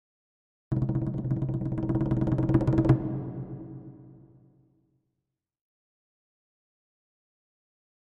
Drums Percussion Danger - Fast Drumming On A Medium Percussion 2